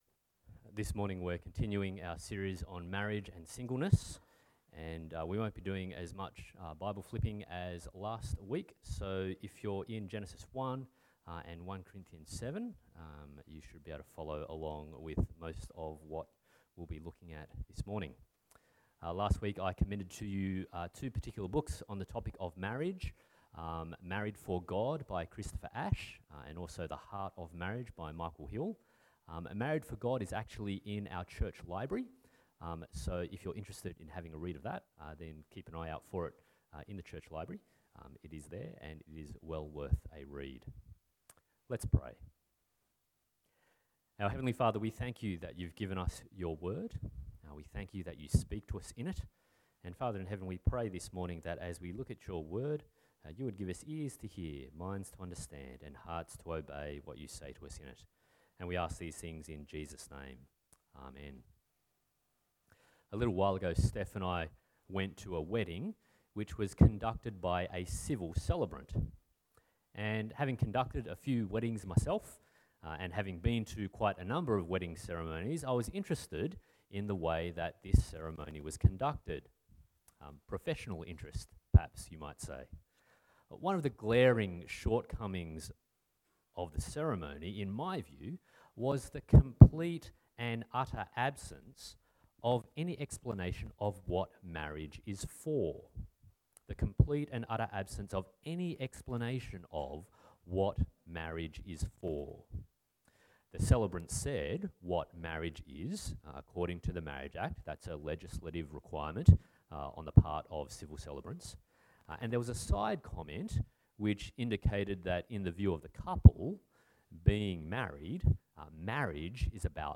Marriage and Singleness Passage: Genesis 1:1-26, Genesis 2:18-25, 1 Corinthians 7:1-5 Service Type: Sunday Morning « What is Marriage?